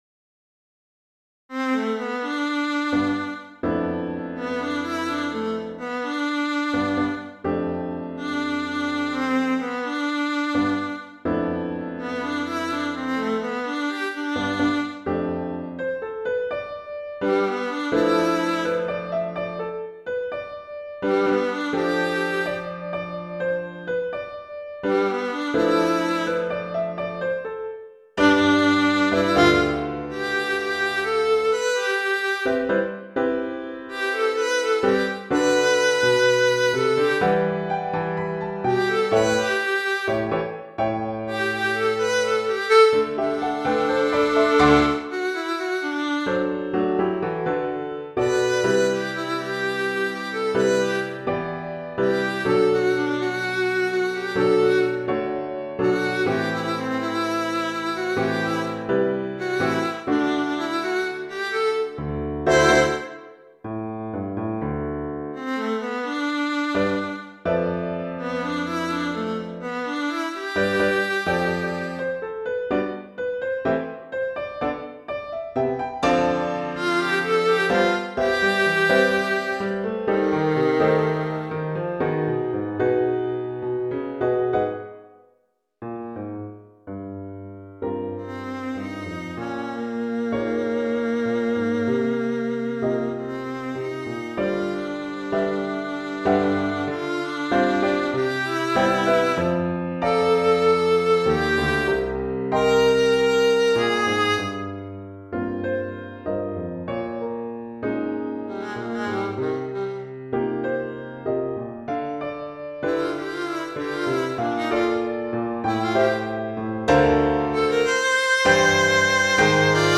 for Viola & Piano